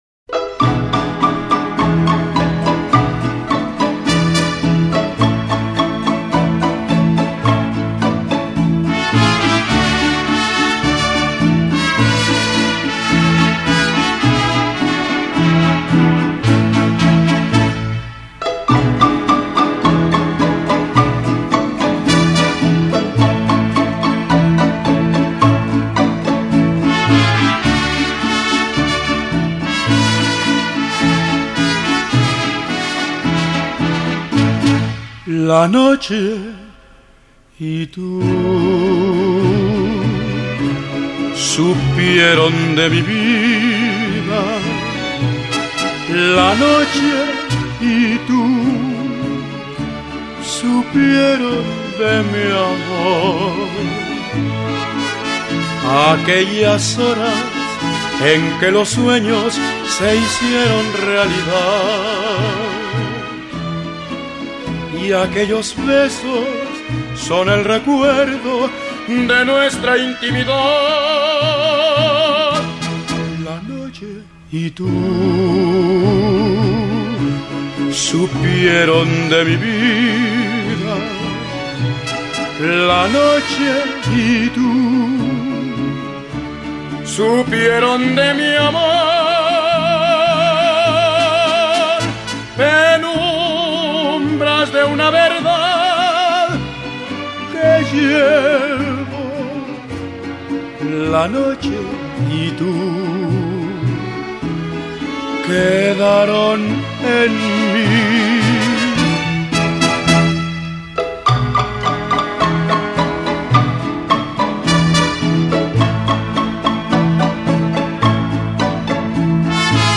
Un sencillo bolero